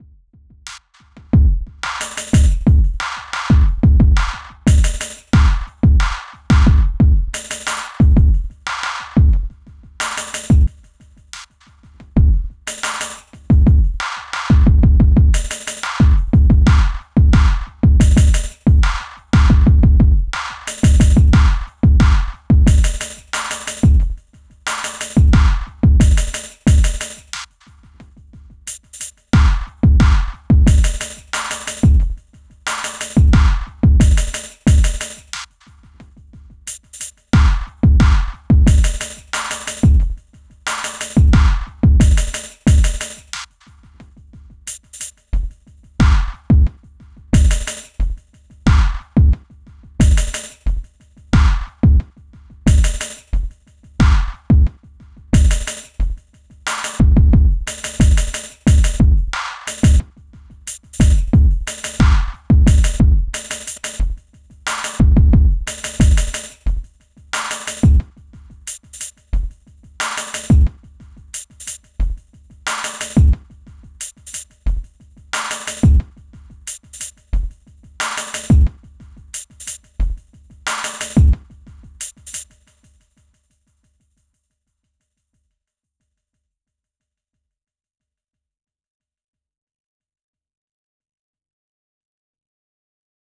Pieza de Break Beat-Down Tempo
Música electrónica
percusión
repetitivo
rítmico
sintetizador